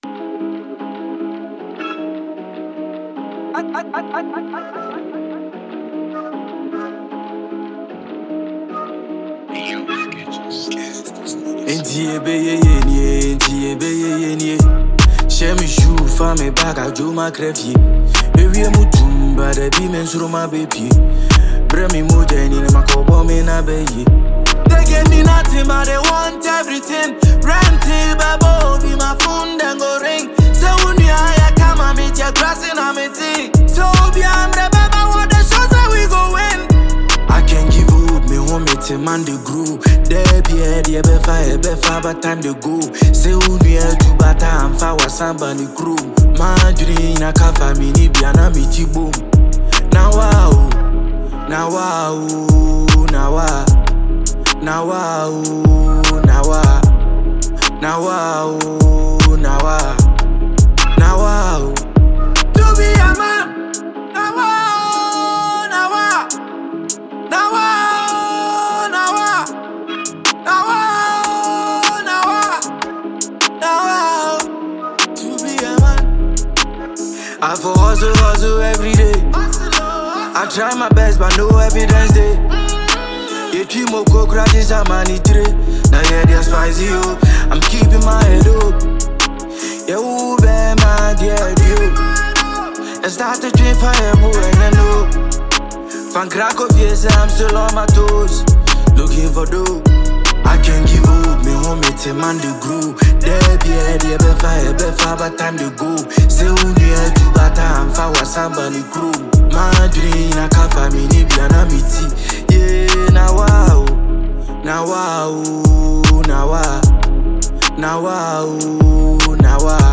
New one from Ghanaian rapper